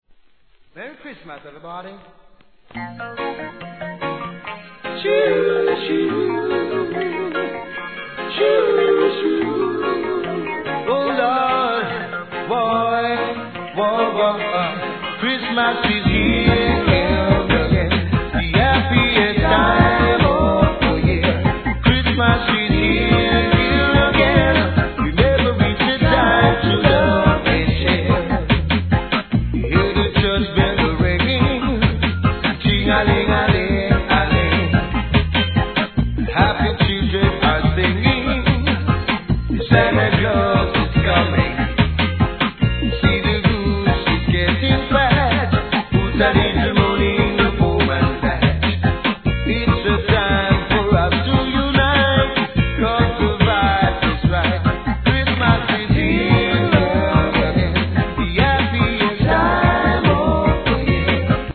REGGAE
曲調はそこらのPOPSなノリとは違えど、CHRISTMASが特別な日で幸せな日だってことは変わりありませんё